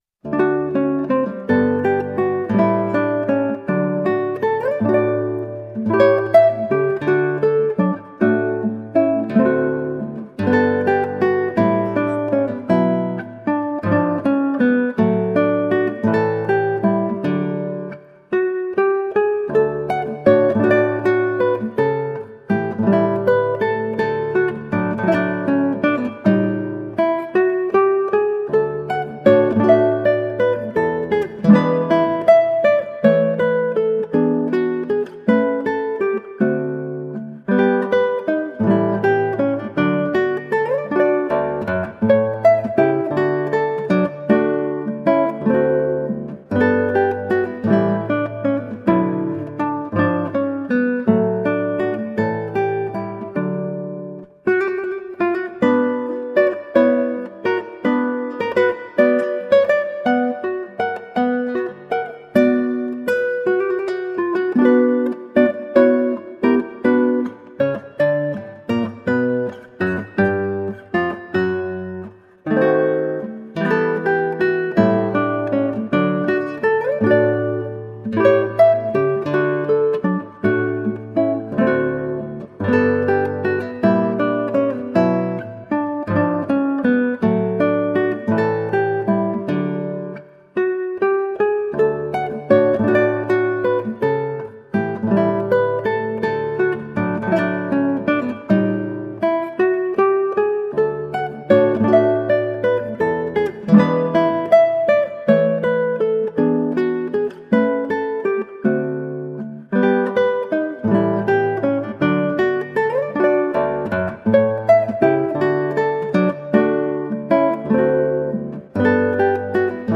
Colorful classical guitar.
Instrumental
Classical Guitar